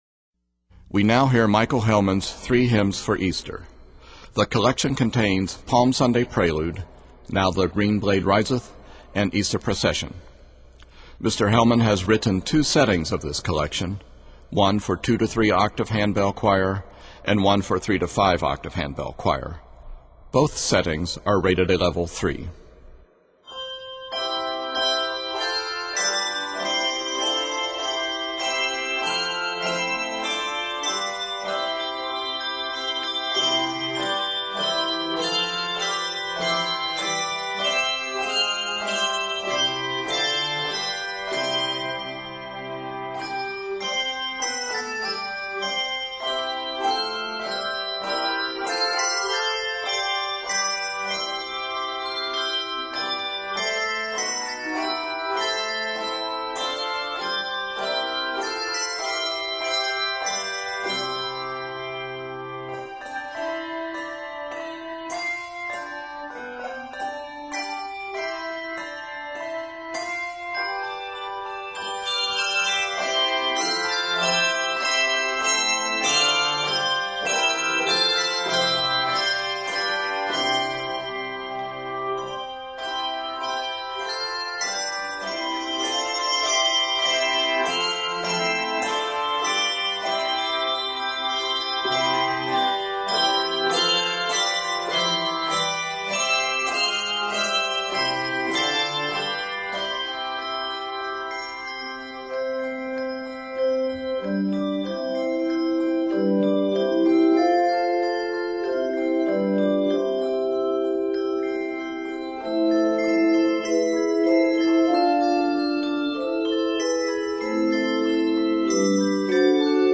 Octaves: 2-5